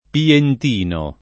pientino [ pient & no ]